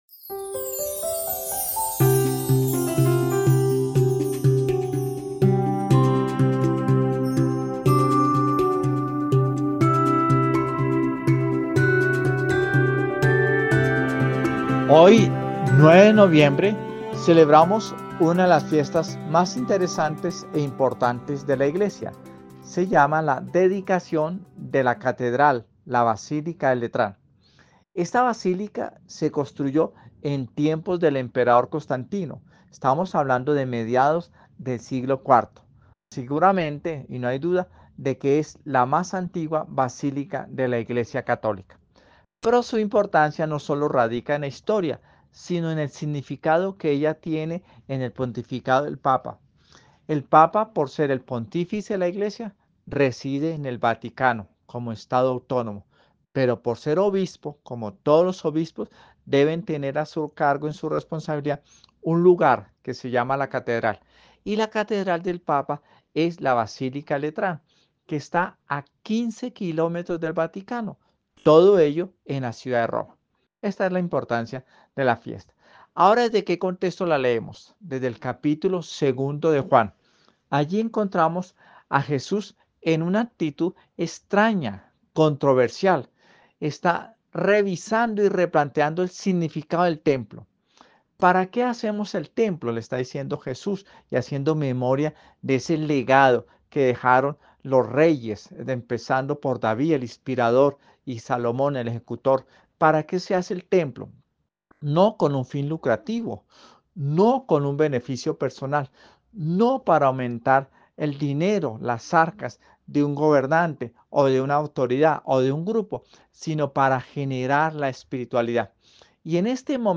Comentario del padre